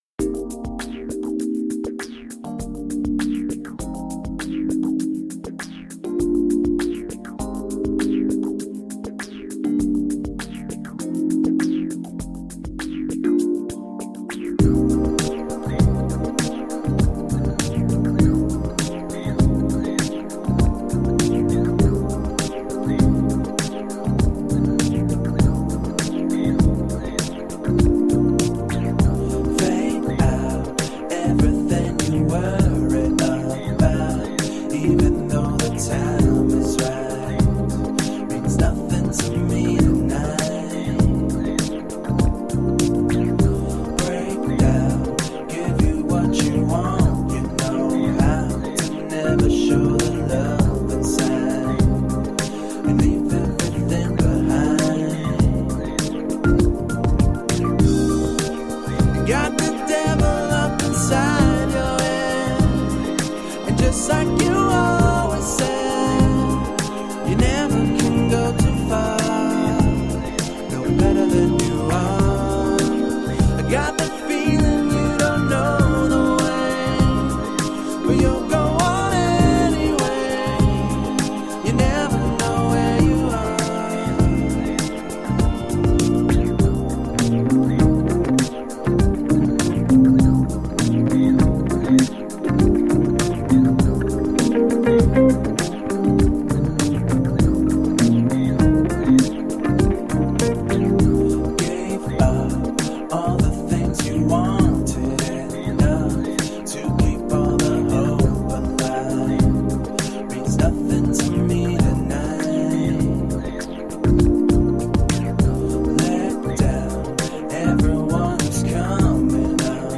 Soulful electro pop.
Tagged as: Electro Rock, Rock, Electro Pop